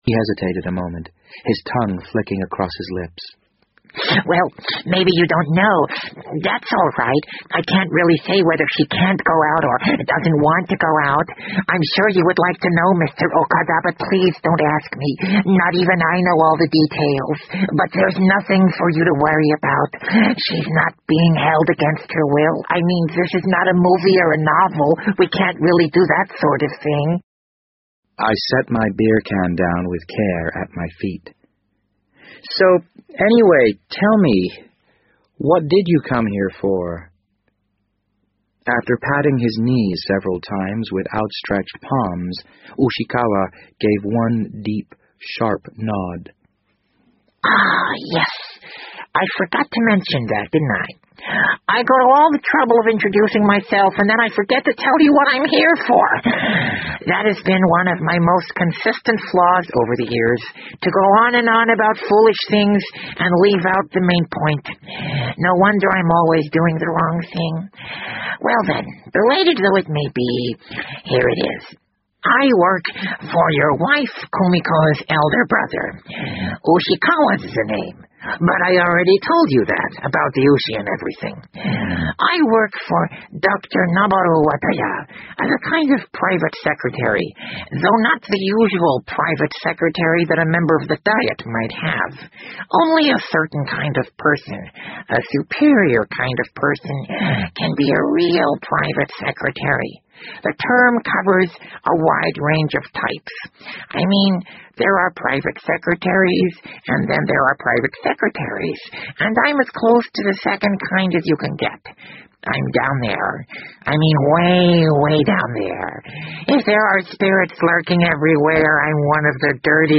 BBC英文广播剧在线听 The Wind Up Bird 011 - 7 听力文件下载—在线英语听力室